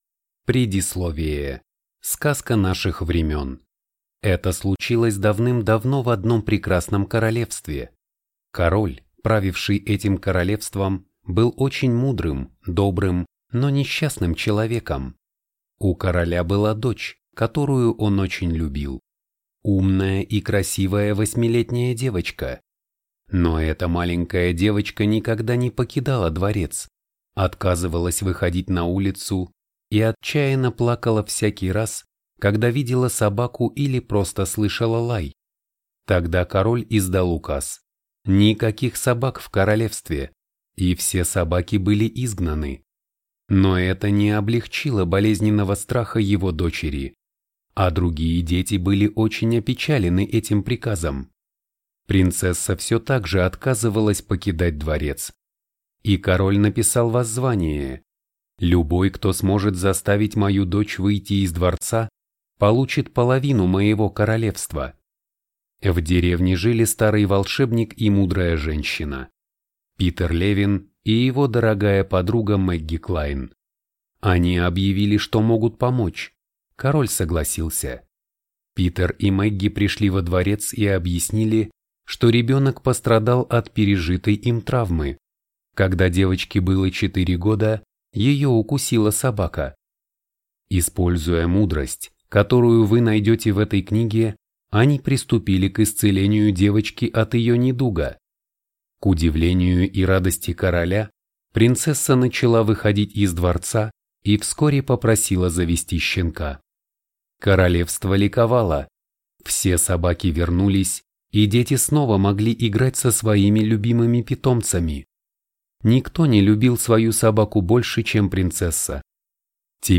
Аудиокнига Воспитываем, не травмируя. Руководство для родителей по развитию в детях уверенности, стойкости и оптимизма | Библиотека аудиокниг